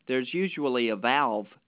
To test the simulation, I obtained a segment of speech which had been digitized and stored on a computer. The segment was a male speaker saying,